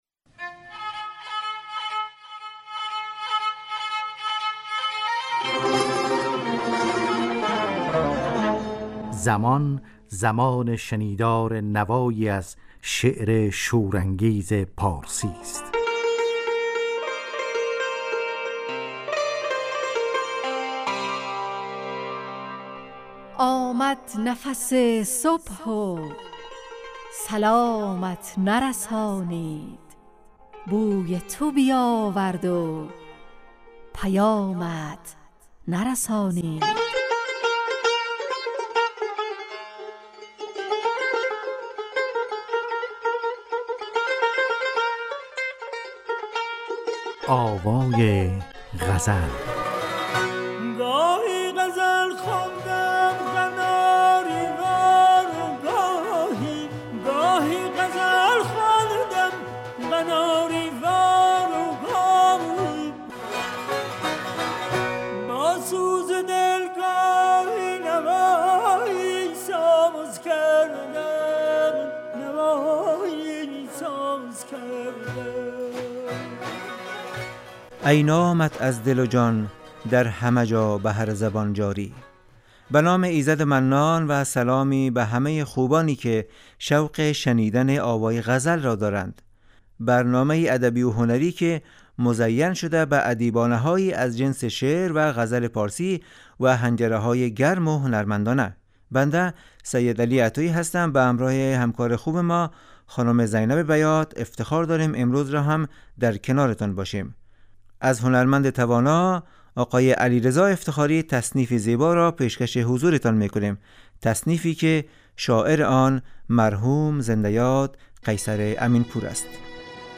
آوای غزل نواهنگ رادیویی در جهت پاسداشت زبان و ادبیات فارسی . خوانش یک غزل فاخر از شاعران پارسی گوی و پخش تصنیف زیبا از خوانندگان نامی پارسی زبان .